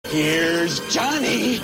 Texture-Pack/assets/minecraft/sounds/mob/zombie/woodbreak.ogg at master
woodbreak.ogg